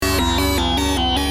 Free MP3 vintage Sequential circuits Pro-600 loops & sound effects 2
Sequencial Circuits - Prophet 600 44